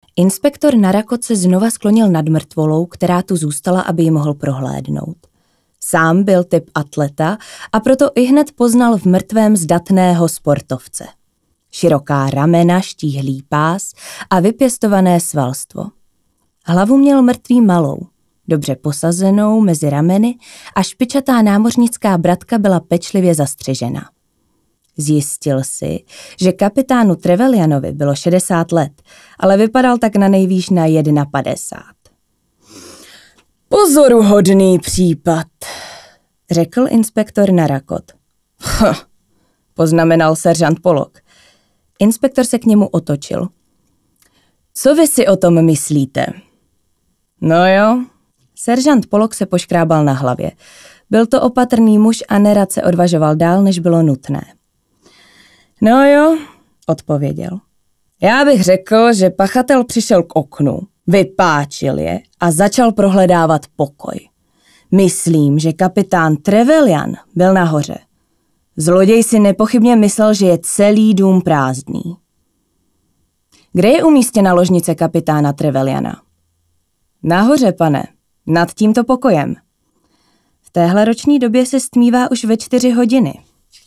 ukázka audio kniha:
audio-kniha.mp3